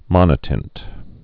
(mŏnə-tĭnt)